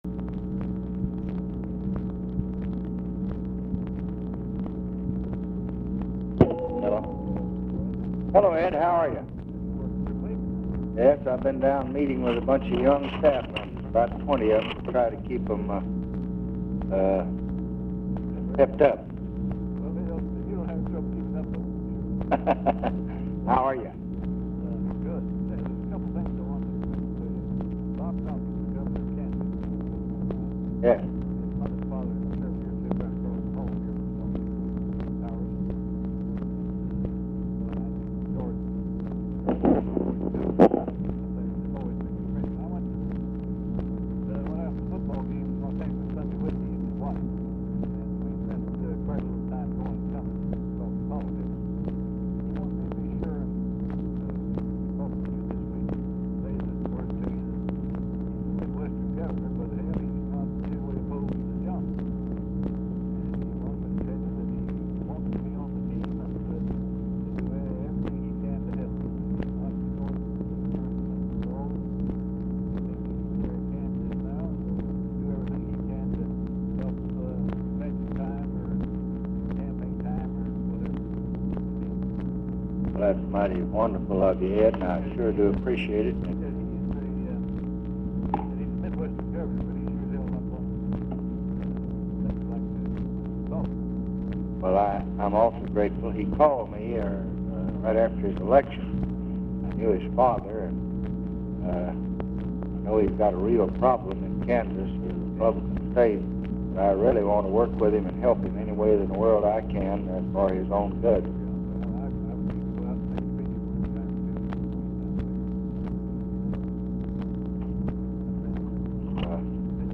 Telephone conversation # 11375, sound recording, LBJ and EDWARD LONG, 1/18/1967, 8:44PM
LONG IS ALMOST INAUDIBLE
Dictation belt